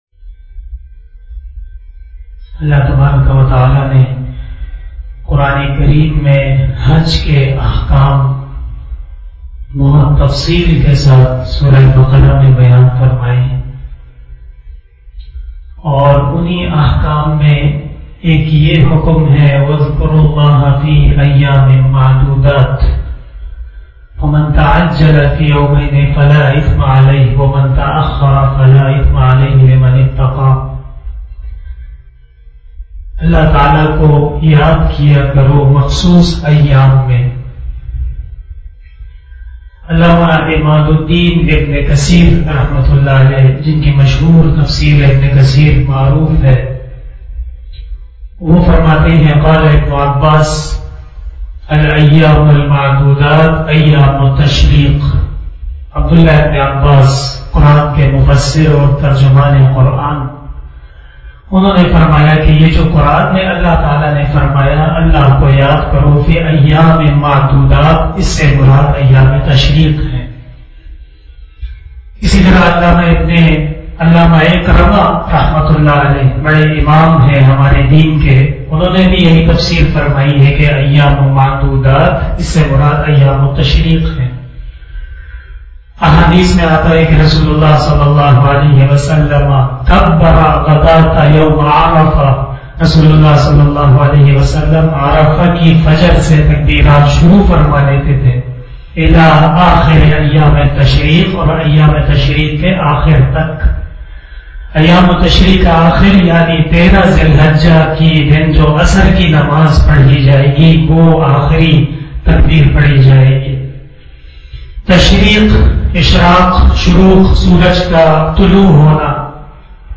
036 After asar Namaz Bayan 20 July 2021 (09 Zilhajjah 1442HJ) Tuesday